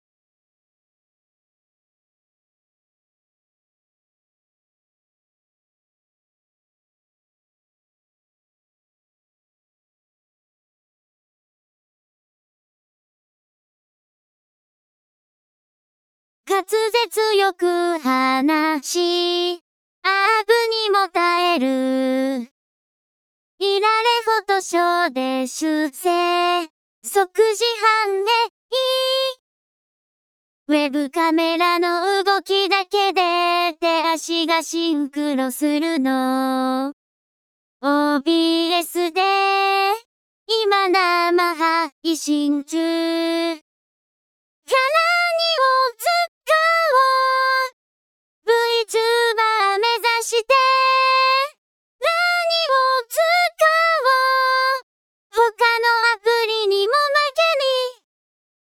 視聴プレーヤー（ボーカルのみバージョン）
※ボーカルはイントロ8小節後、約15秒後から始まります